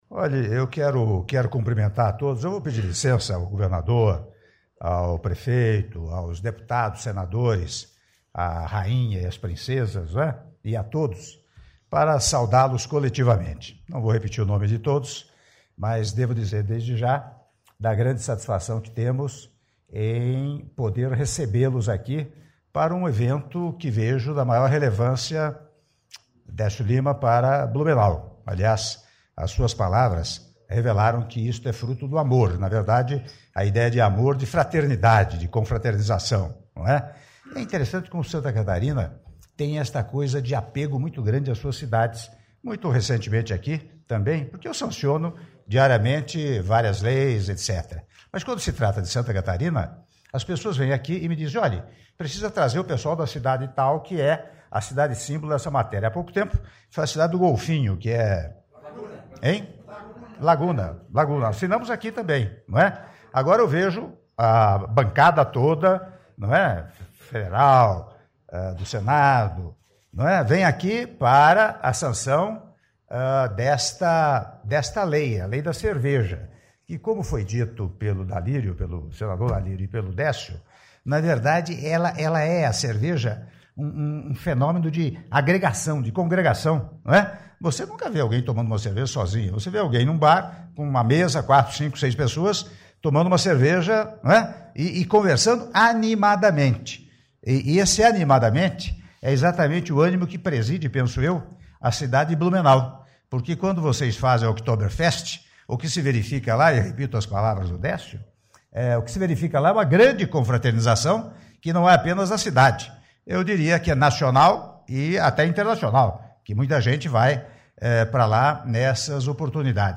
Áudio do discurso do presidente da República, Michel Temer, durante a cerimônia de Sanção da lei que confere à Cidade de Blumenau o título de Capital Nacional da Cerveja - Brasília/DF (05min07s)